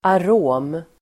Uttal: [ar'å:m]